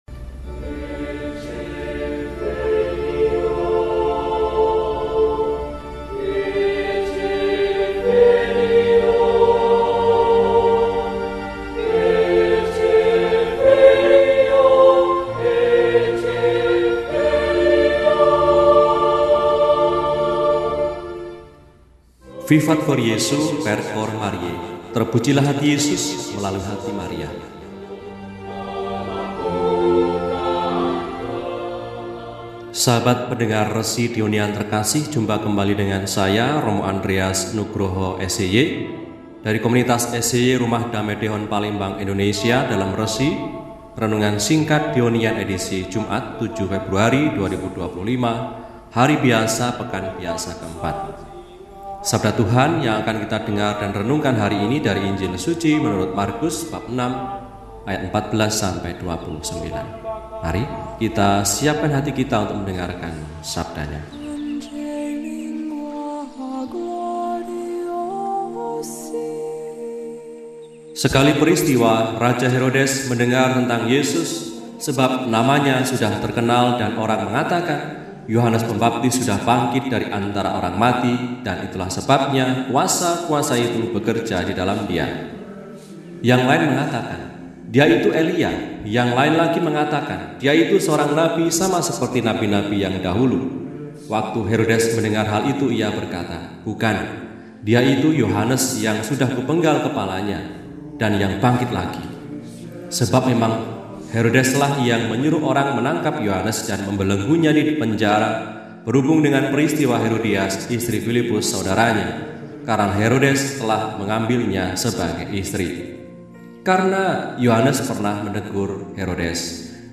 Jumat, 07 Februari 2025 – Hari Biasa Pekan IV – RESI (Renungan Singkat) DEHONIAN